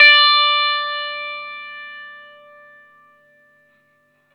R12NOTE D +2.wav